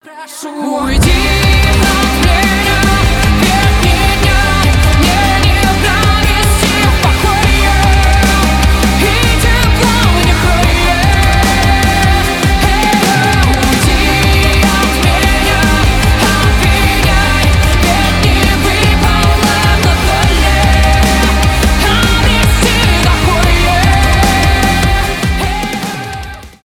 альтернатива rock
рок СКАЧАТЬ 0 0 1.02mb Вчера